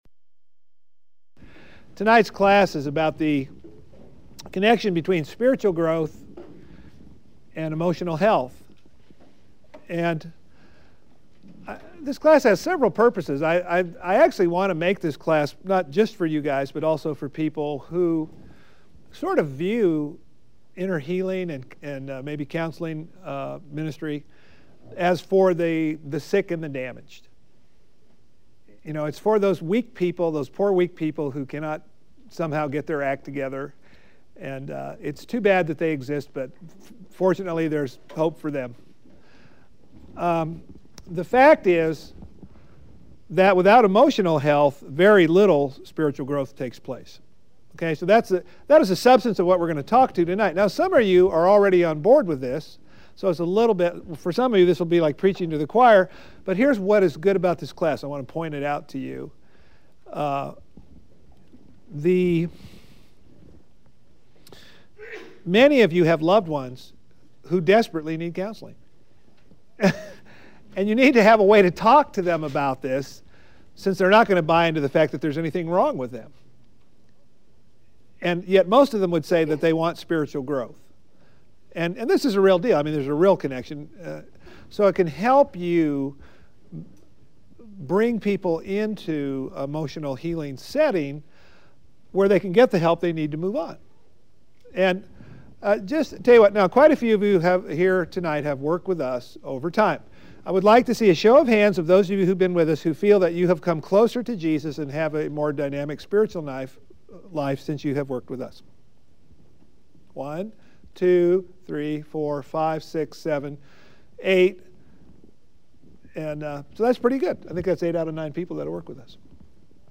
Firehouse Ministries, Inc. offers free spiritual exercises, classes and more to help you develop spiritually.